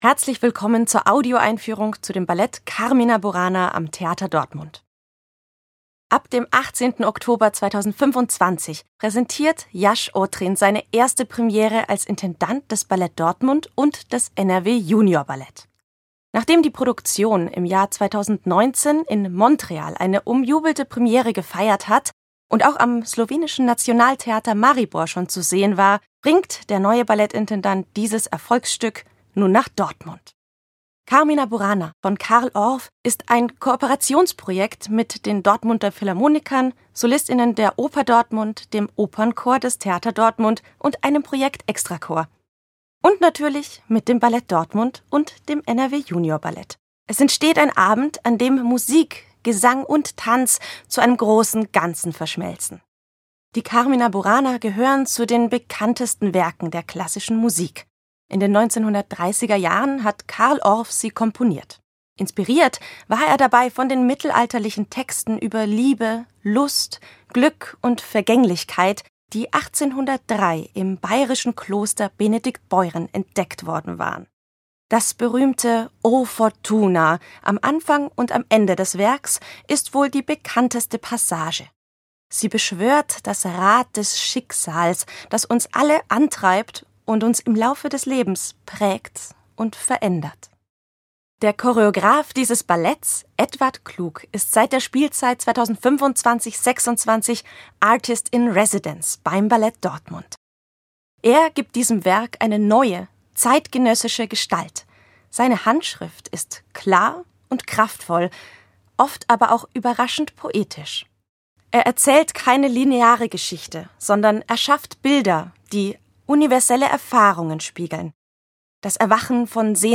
tdo_Audioeinfuehrung_Carmina.mp3